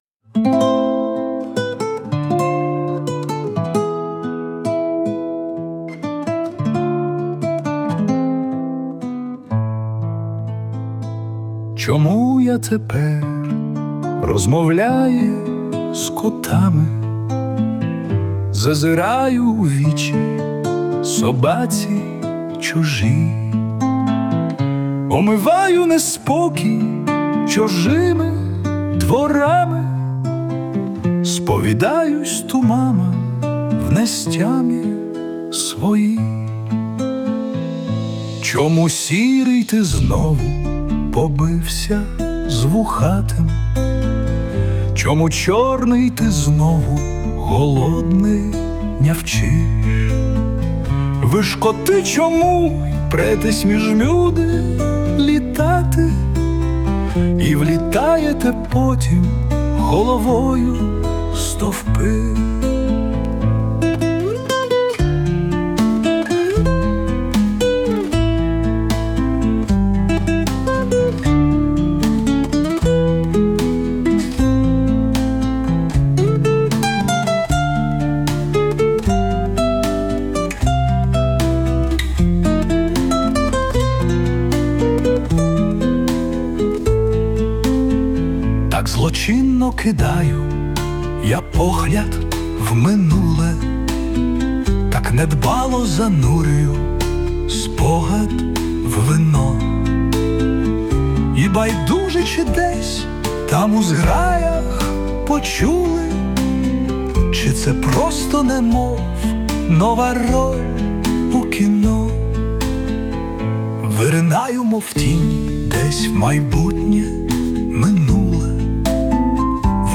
Кавер на власне виконання
але тут якраз моє виконання ... не почищене )
Дуже гарна пісня! 12 16